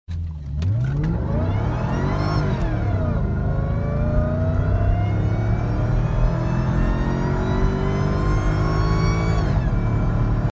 This car is still very competitive (with a couple of mods) even 20+ years later. It’s won many hearts with its supercharged V8, the M113K.